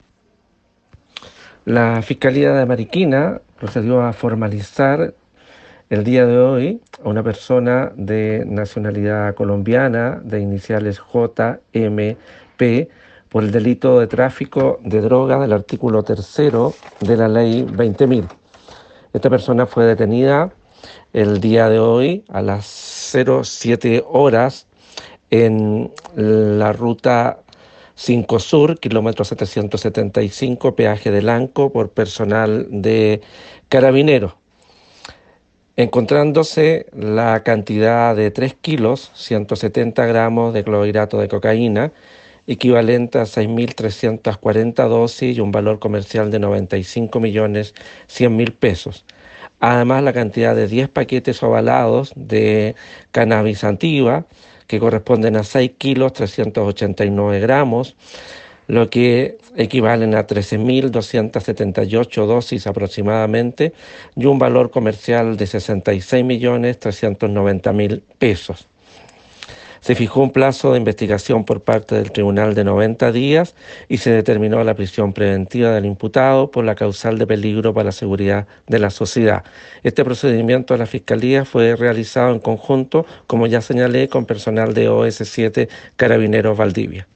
Fiscalía a través del fiscal Alejandro Ríos.